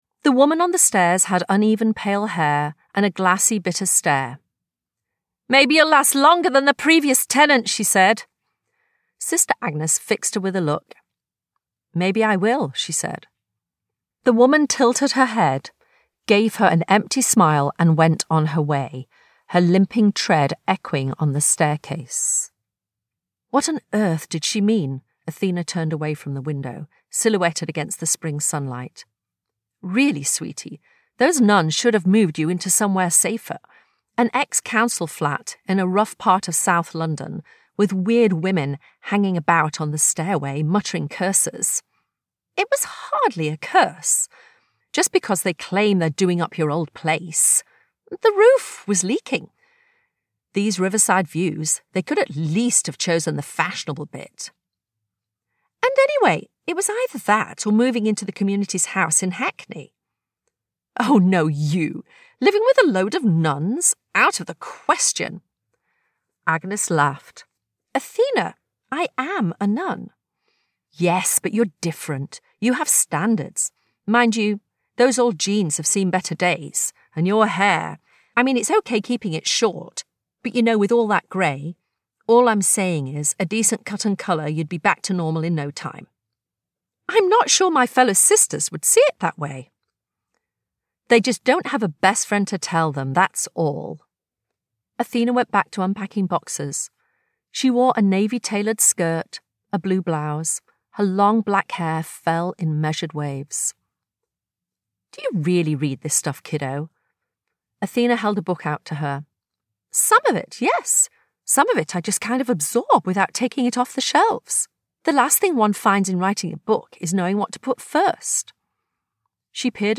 The Book of Extraordinary Amateur Sleuth and Private Eye Stories - Vibrance Press Audiobooks - Vibrance Press Audiobooks